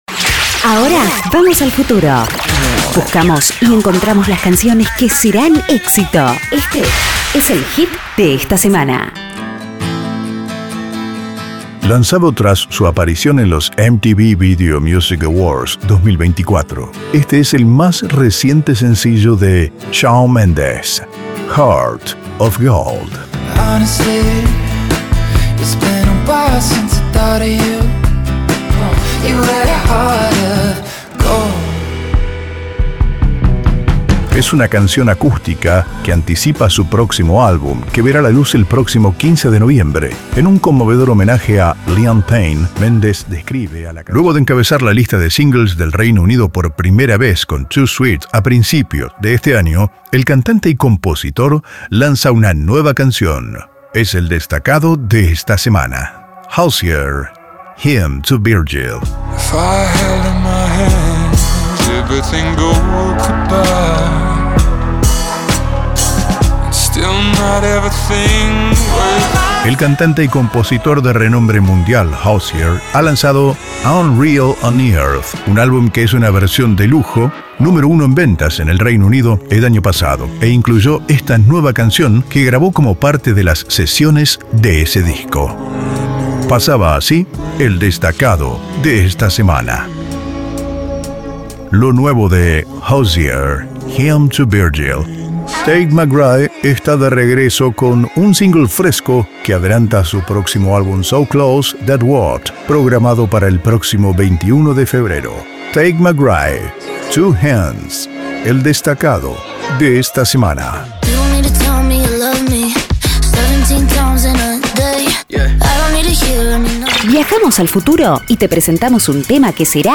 El tema de la semana, un nuevo lanzamiento, una canción novedosa, la anunciamos y comentamos su procedencia.